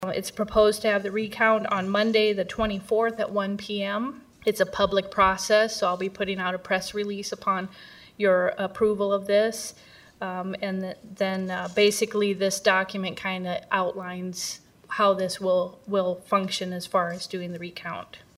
WOODBURY COUNTY ELECTIONS COMMISSIONER MICHELLE SKAFF TOLD WOODBURY COUNTY SUPERVISORS AT THEIR TUESDAY MEETING THAT A RECOUNT REQUEST HAD BEEN MADE: